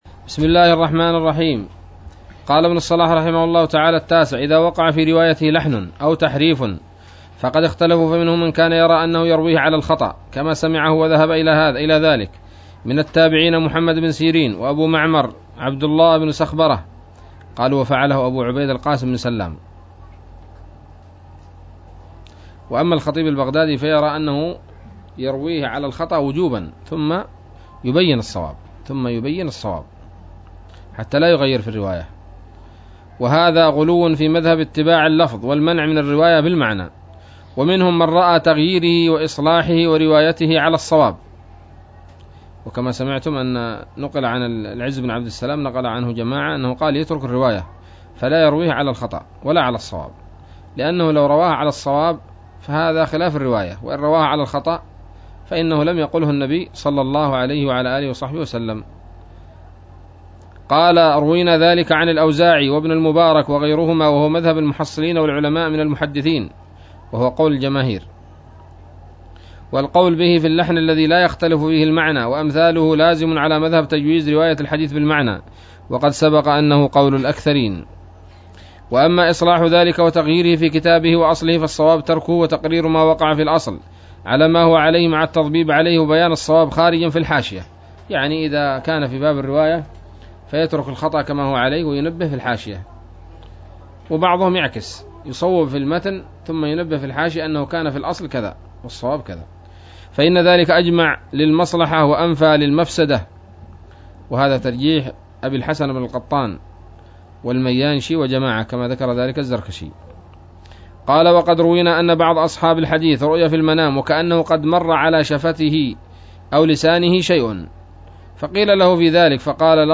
الدرس الحادي والثمانون من مقدمة ابن الصلاح رحمه الله تعالى